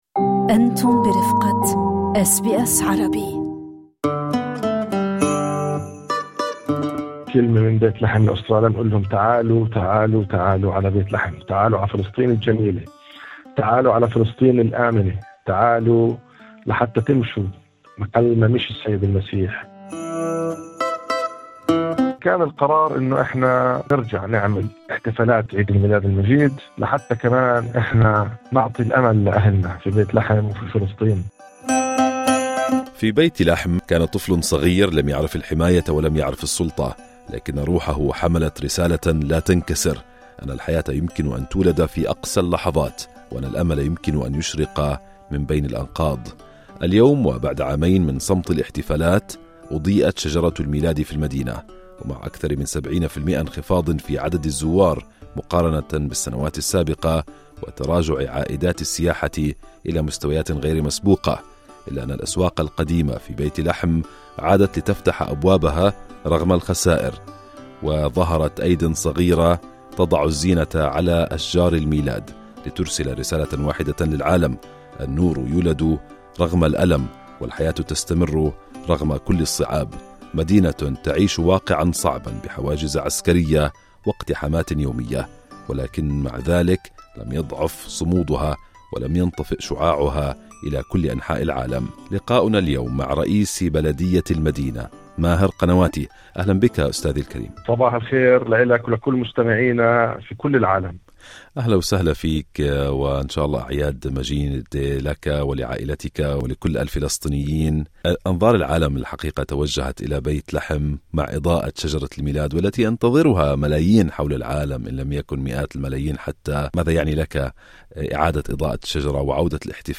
بعد عامين من الغياب القسري، عادت بيت لحم في الضفة الغربية ذا العام لإحياء احتفالات عيد الميلاد، في خطوة حملت أبعاداً اجتماعية واقتصادية وسياسية، أكثر من كونها احتفالًا تقليديًا. التقينا برئيس بلدية المدينة ماهر قنواتي وسألناه عما تعنيه عودة الاحتفالات إلى المدينة التاريخية.